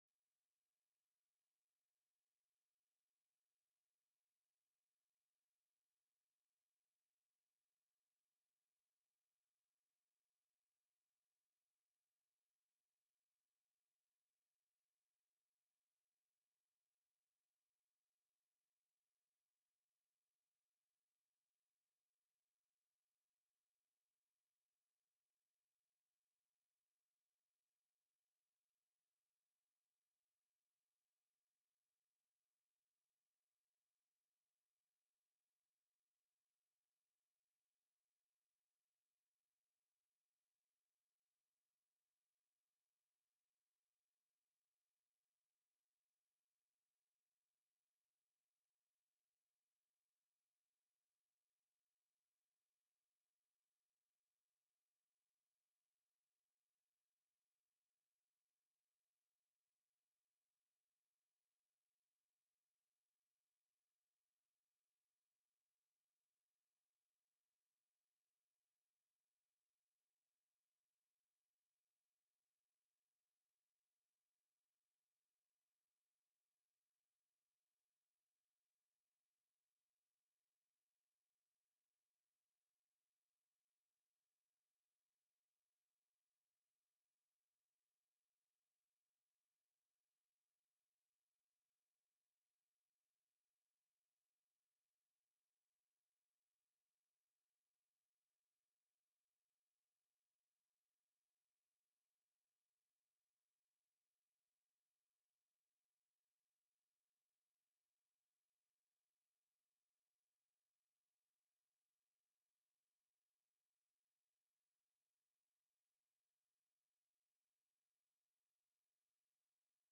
05/16/2025 04:00 PM Senate CONFERENCE COMMITTEE ON HB53 AND HB55
The audio recordings are captured by our records offices as the official record of the meeting and will have more accurate timestamps.